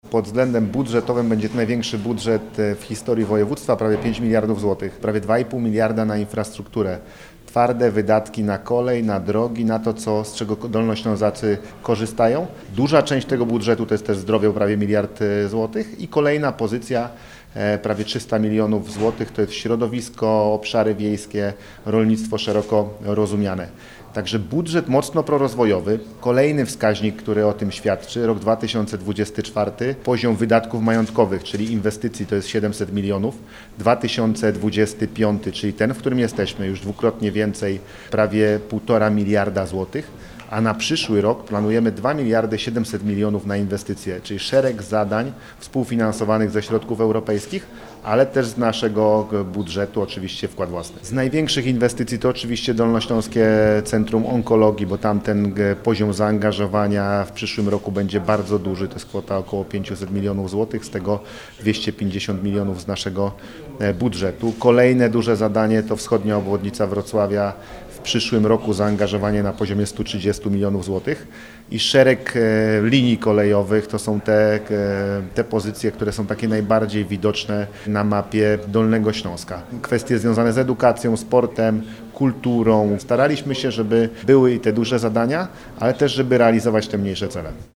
Podczas konferencji prezentującej założenia budżetowe członkowie zarządu województwa podkreślili, że przyszłoroczny budżet będzie większy od tegorocznego o 800 mln zł.
To budżet kontynuacji dużych zadań inwestycyjnych, jak i szeregu nowych pomysłów – zaznacza Paweł Gancarz marszałek Województwa Dolnośląskiego.